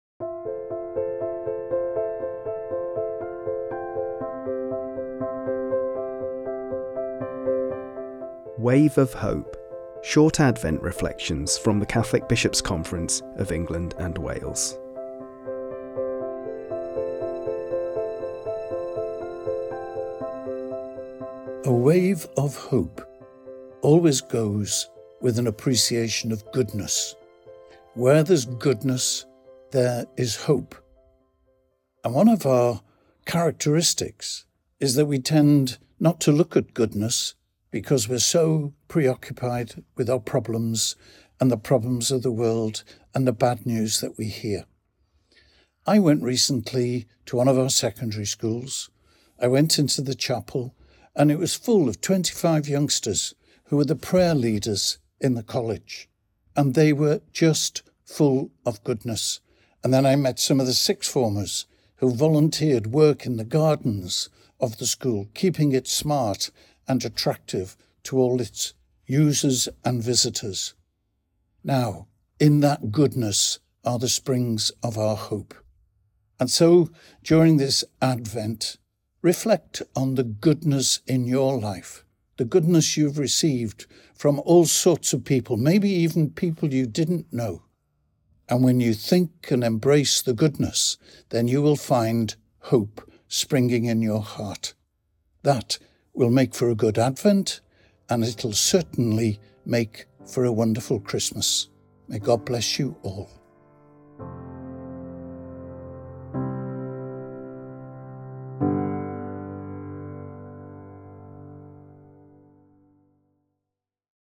Our final Wave of Hope reflection for Advent comes from the 11th Archbishop of Westminster, Cardinal Vincent Nichols.